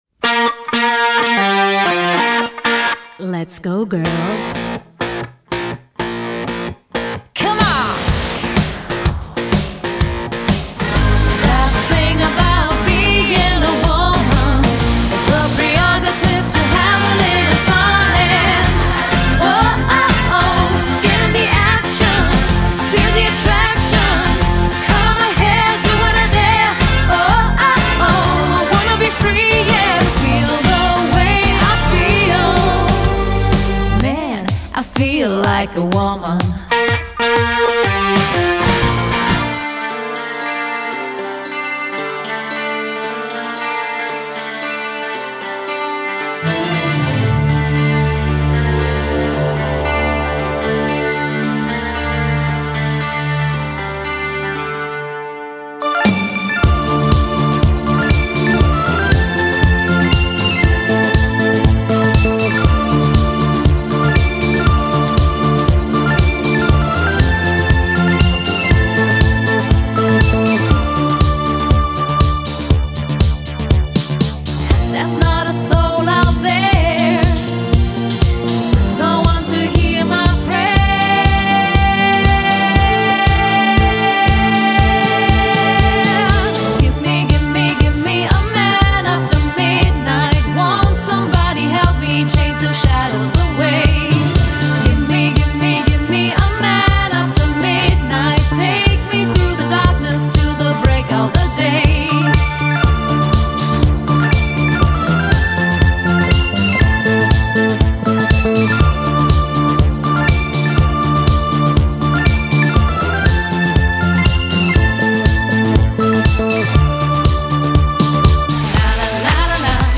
Das Female Gesangsduo für unvergessliche Momente
Cover Sängerinnen